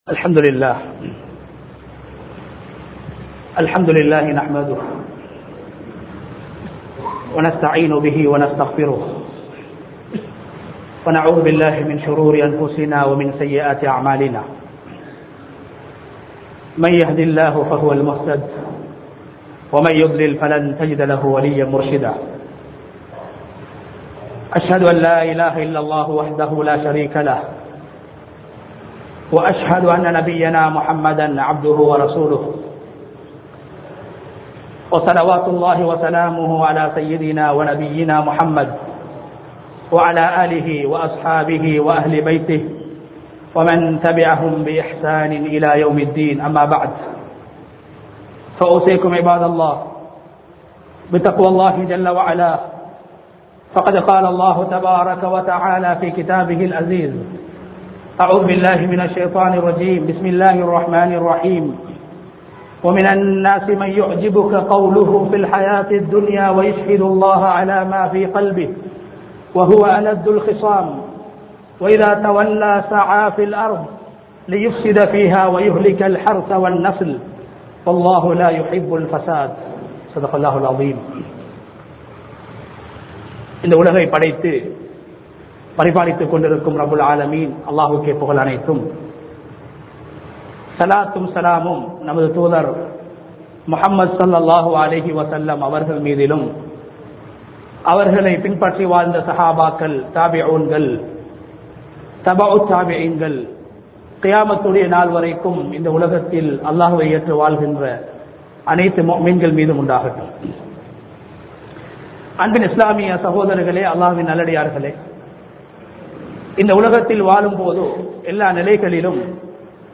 குழப்பம் செய்யாதீர்கள் | Audio Bayans | All Ceylon Muslim Youth Community | Addalaichenai
Ilukkuwaththa Jumua Masjidh